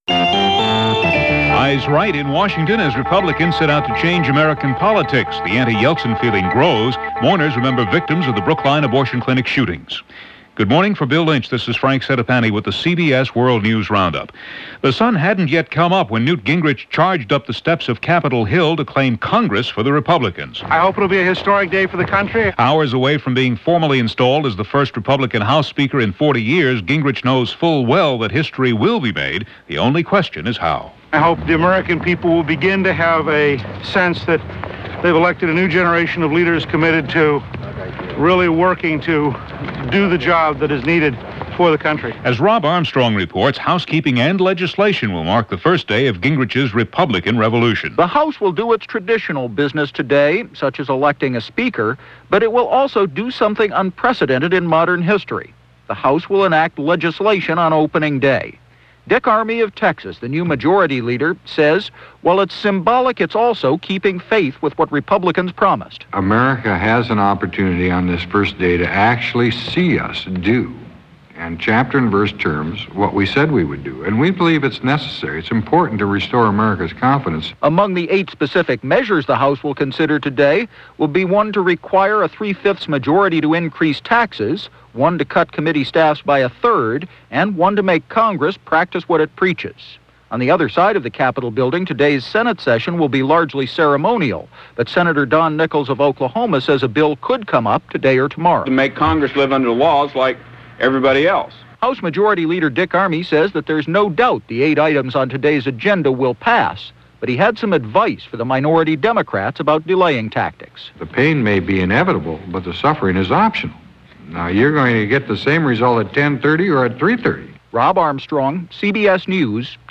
And that’s a small slice of what happened, this January 4, 1995 as reported by The CBS World News Roundup.